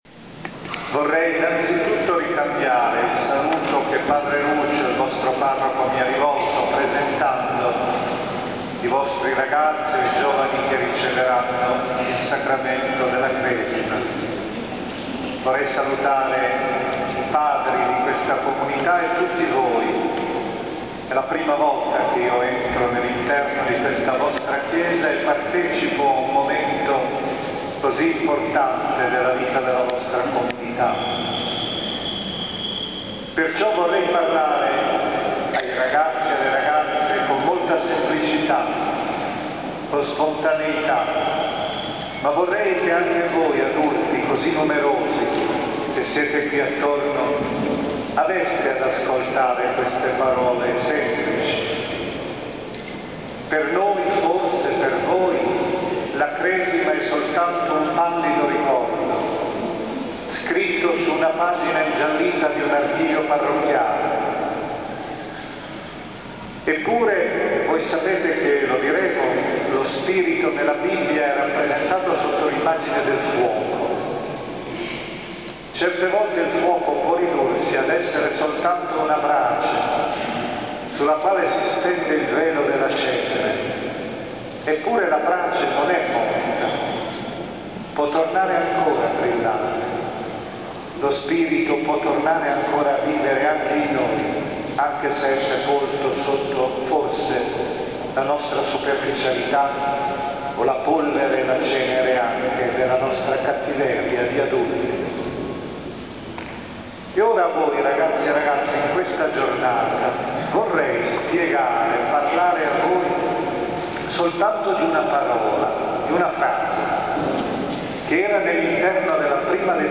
omelia.mp3